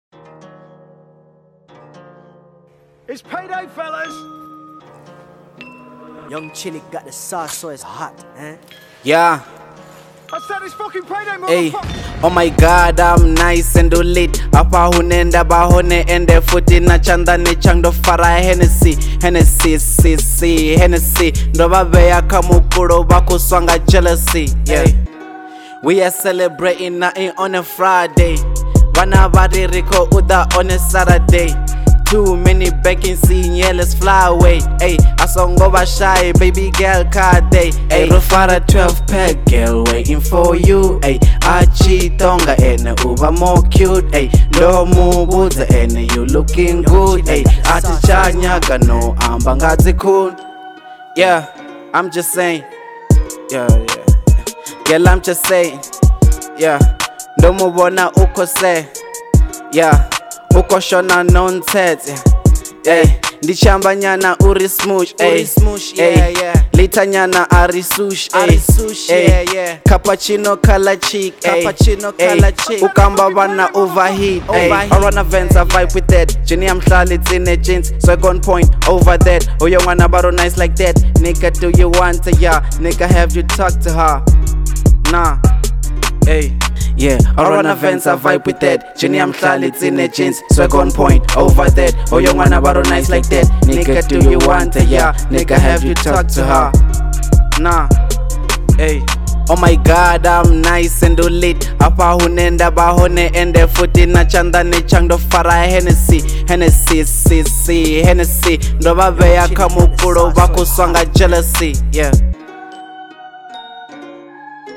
01:54 Genre : Venrap Size